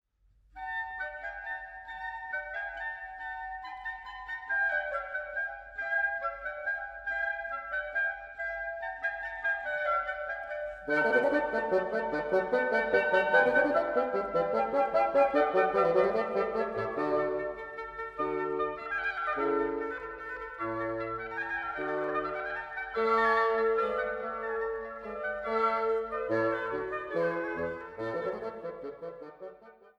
Flöte
Oboe und Englischhorn
Klarinette
Fagott
Harfe